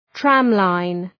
Προφορά
{‘træmlaın}